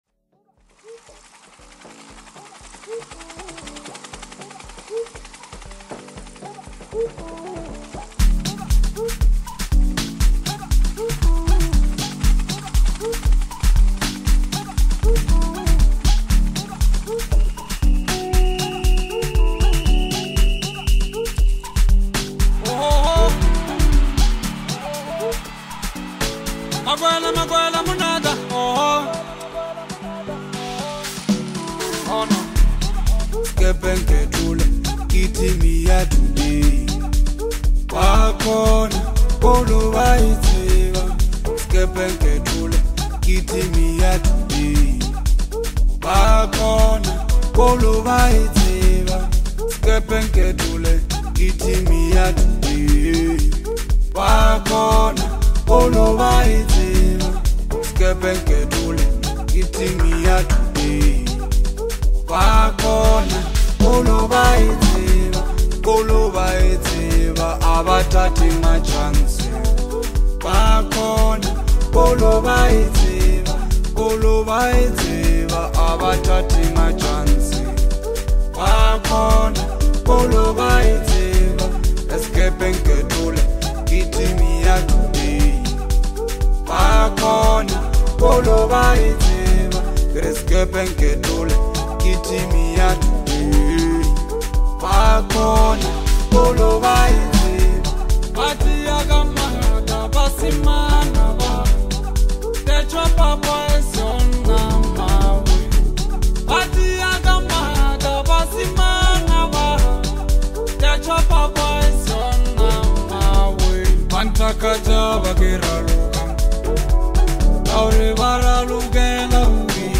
Bolo House
rhythmic melodies,deep basslines
infectious groove